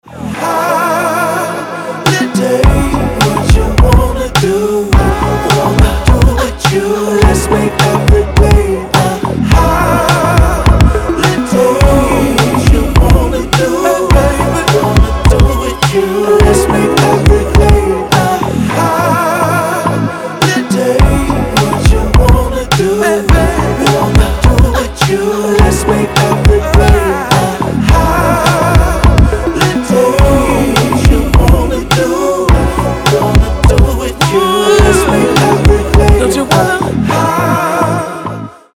• Качество: 320, Stereo
ритмичные
dance
Electronic
RnB